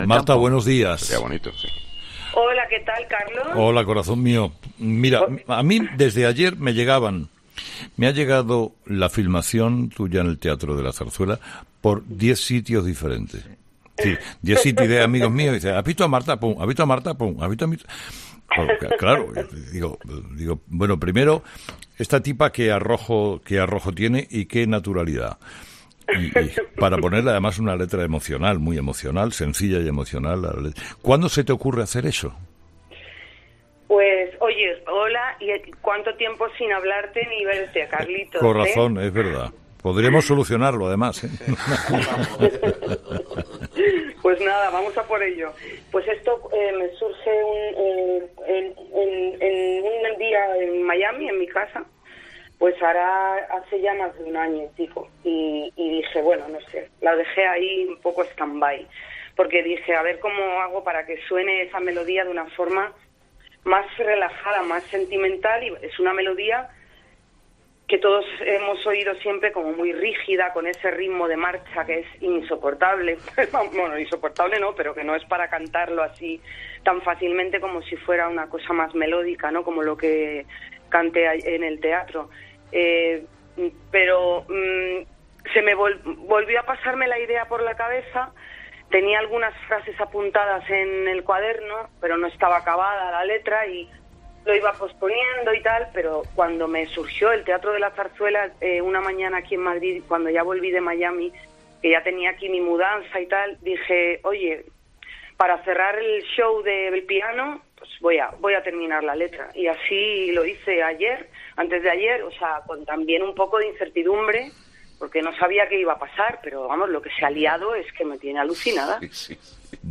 Entrevista a Marta Sánchez por su versión del himno de España